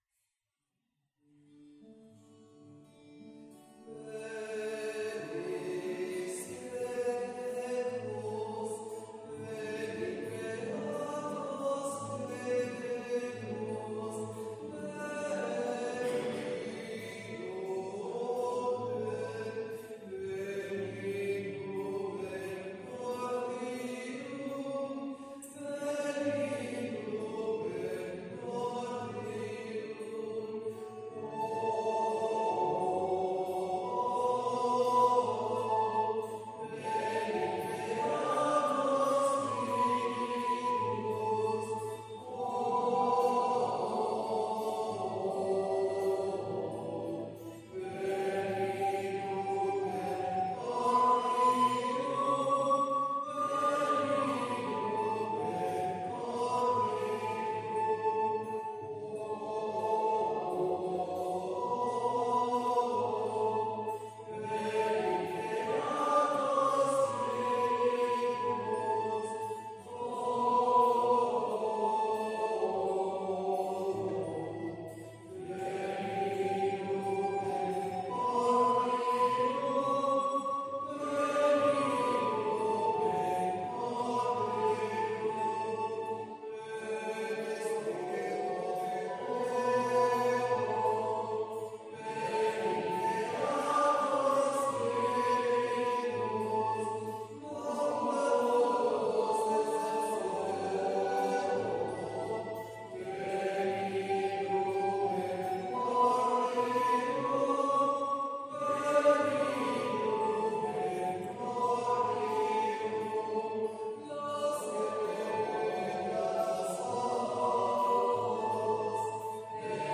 Pregària de Taizé a Mataró... des de febrer de 2001
Església dels maristes de Valldemia - Diumenge 25 de maig de 2025